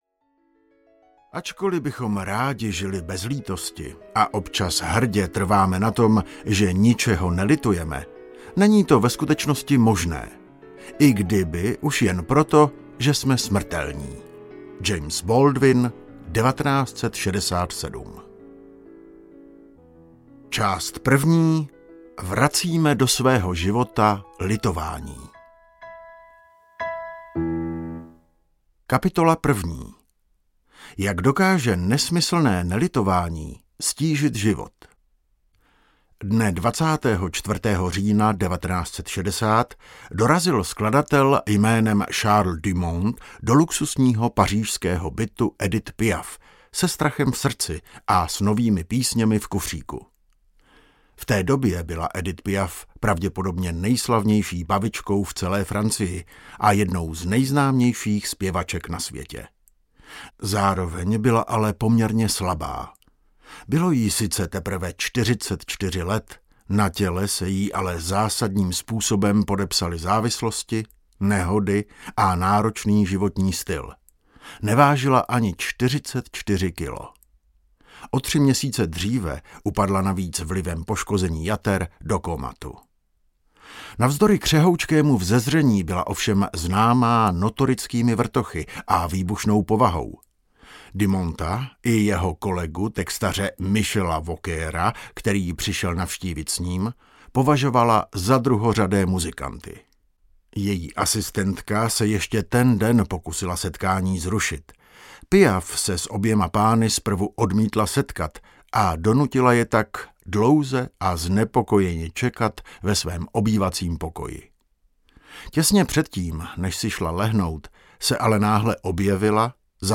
Síla lítosti audiokniha
Ukázka z knihy